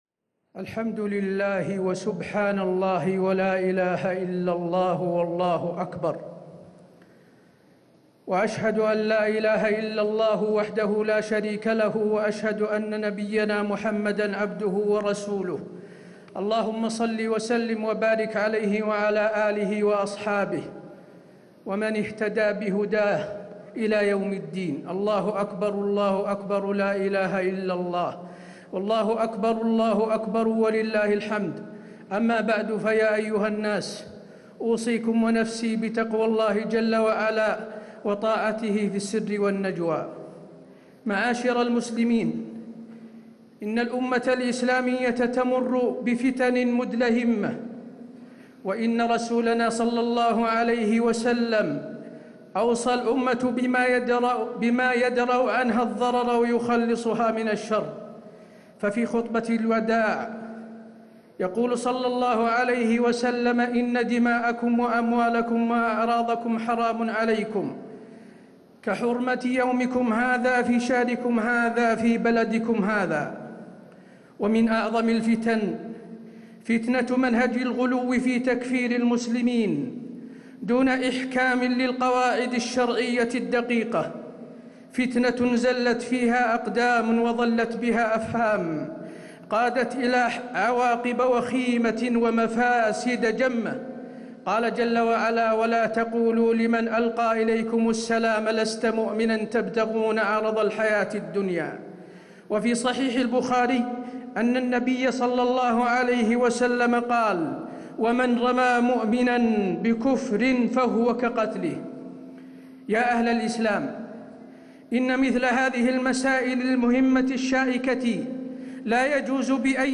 خطبة عيد الأضحى 10 ذو الحجة 1437هـ > خطب الحرم النبوي عام 1437 🕌 > خطب الحرم النبوي 🕌 > المزيد - تلاوات الحرمين